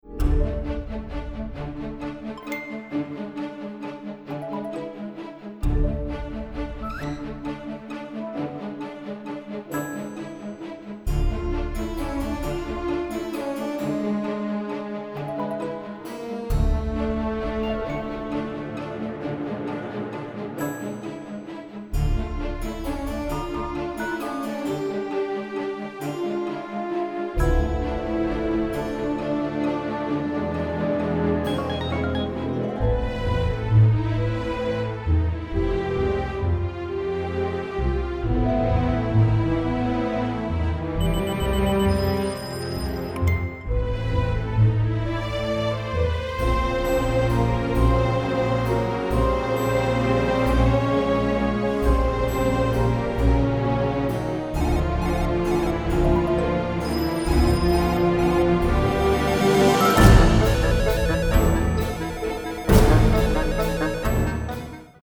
wit, slapstick, mystery and suspense with a classy touch